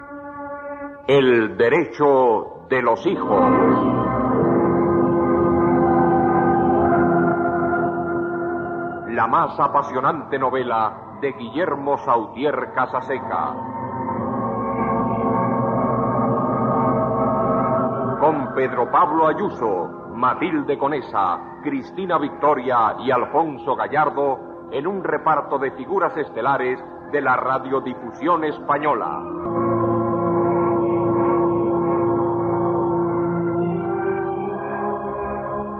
Careta del serial de Guillermo Sautier Casaseca, amb els noms del repartiment
Ficció